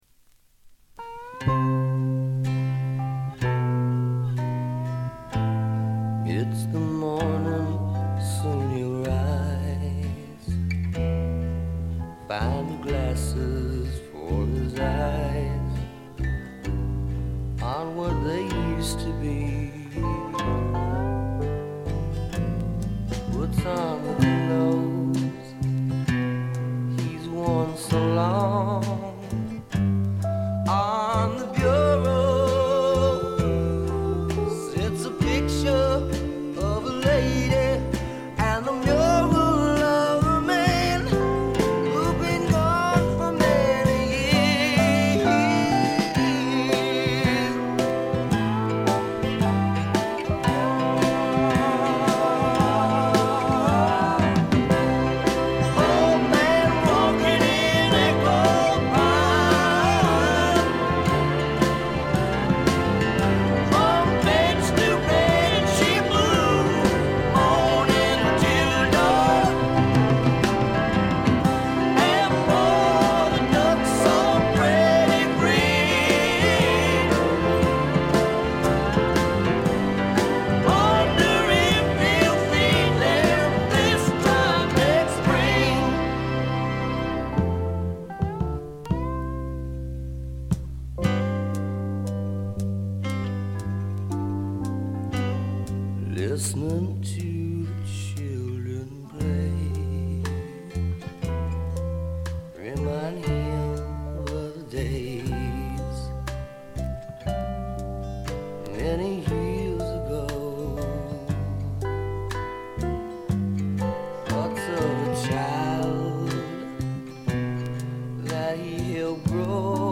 部分試聴ですが、軽微なノイズ感のみ。
試聴曲は現品からの取り込み音源です。